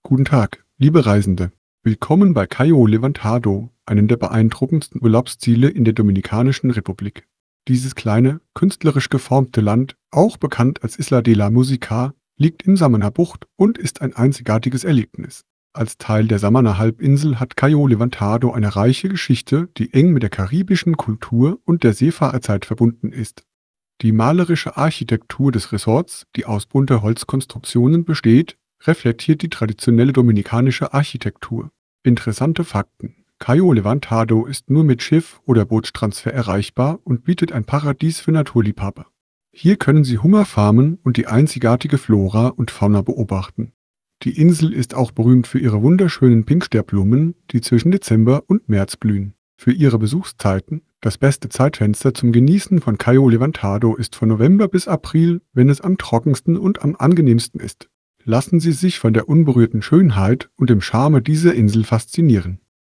karibeo_api / tts / cache / a754bfa5e66c6558e7c9bb901d7d1c69.wav